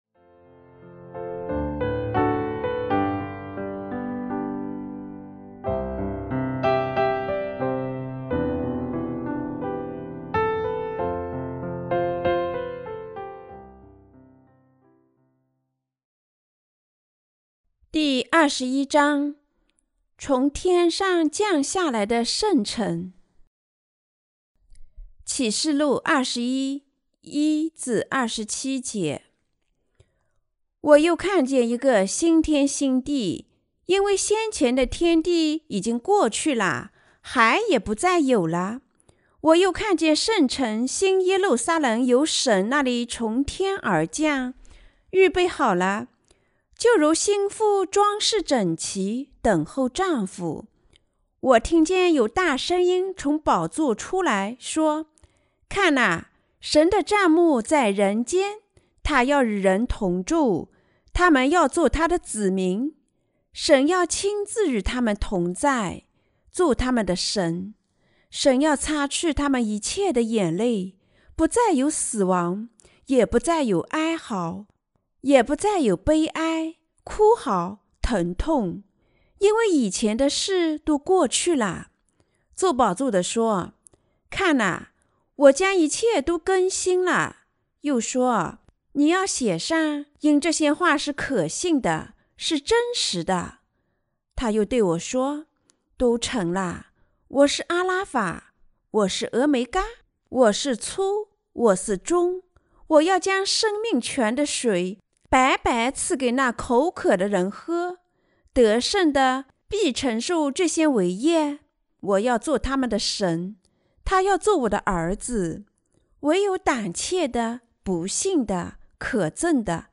關於《啟示錄》的注釋和佈道 - 敵基督者、殉難、被提和千禧年王國的時代來臨了嗎？